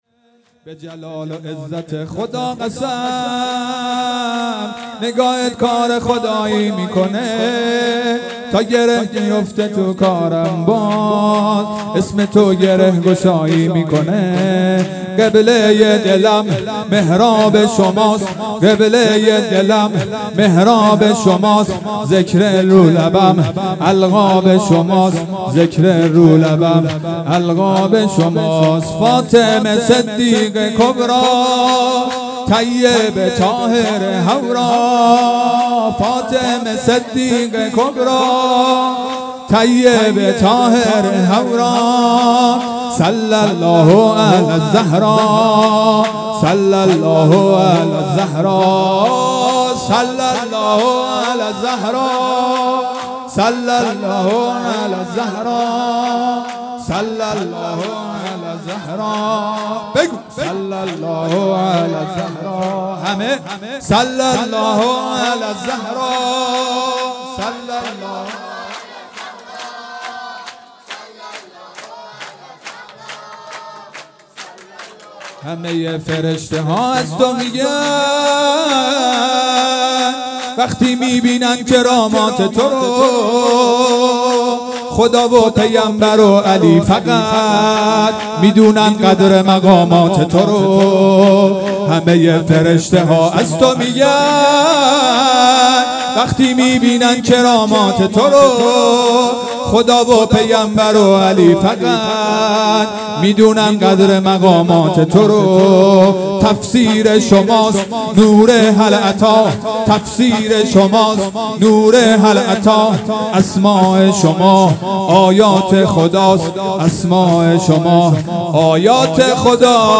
شور - به جلال و عزت خدا قسم
جشن ولادت حضرت زهرا سلام الله علیها